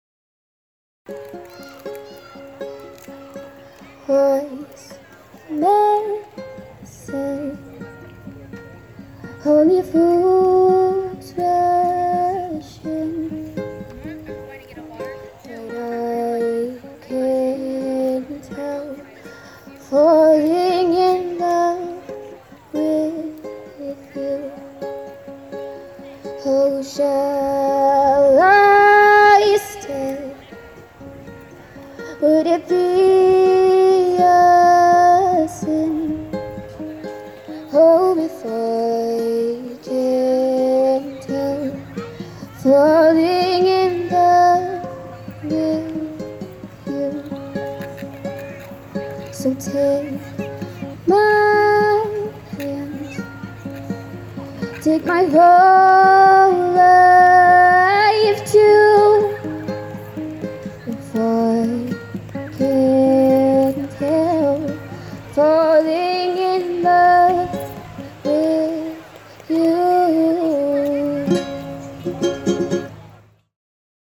acoustic ukulele